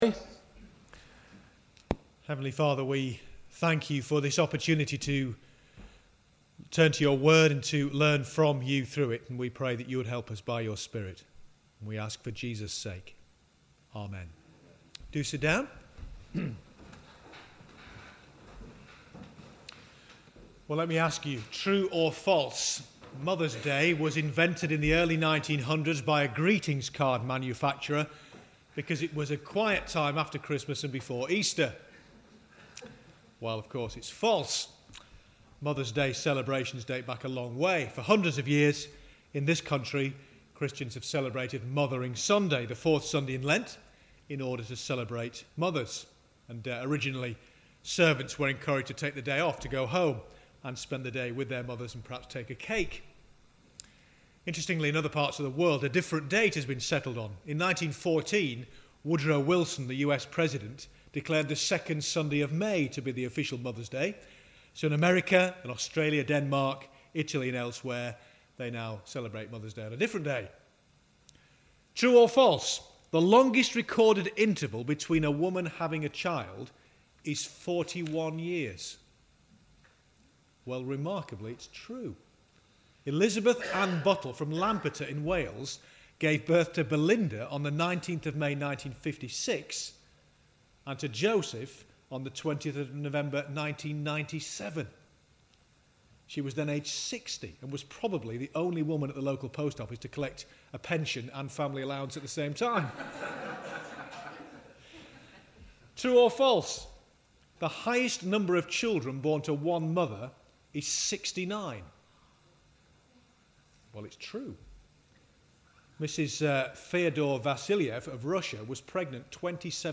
Holiday Club – 1 Corinthians 1:18-25